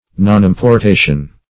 Search Result for " nonimportation" : The Collaborative International Dictionary of English v.0.48: Nonimportation \Non*im`por*ta"tion\, n. Want or failure of importation; a not importing of commodities.